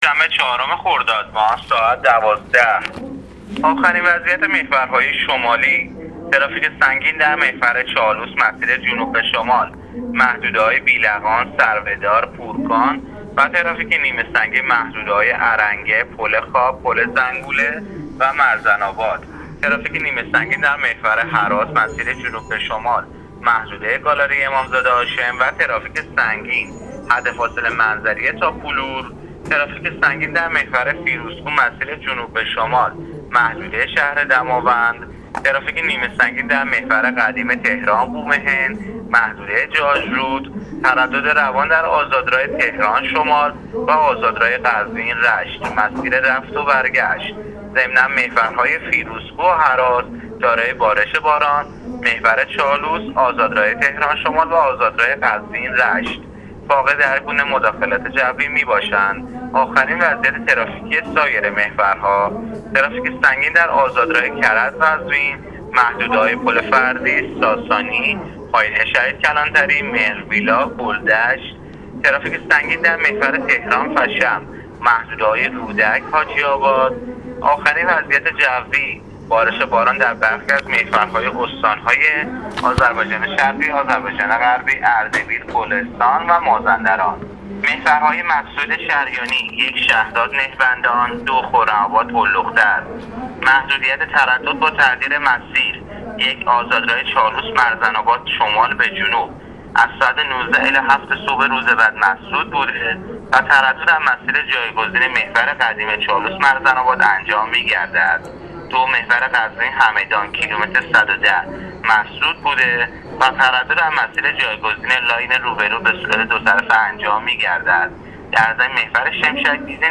گزارش رادیو اینترنتی از وضعیت ترافیکی جاده‌ها تا ساعت ۱۲چهارم خردادماه